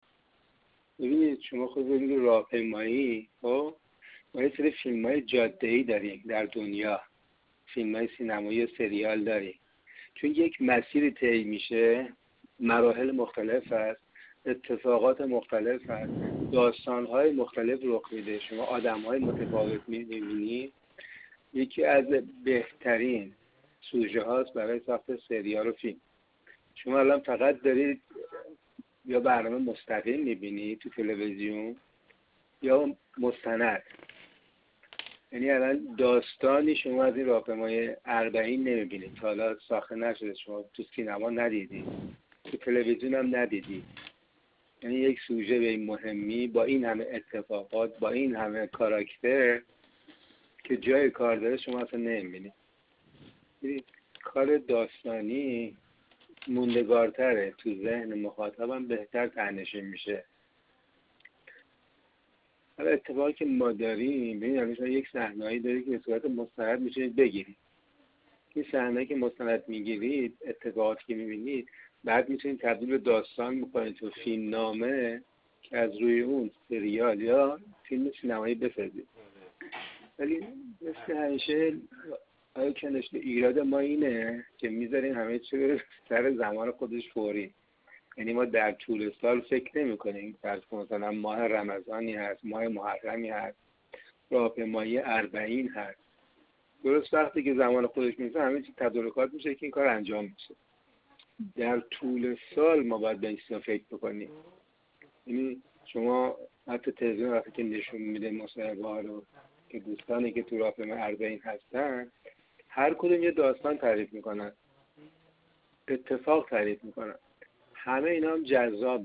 خبرنگار ایکنا با این فعال سینمایی پیرامون راهپیمایی اربعین گفت‌وگویی انجام داده است.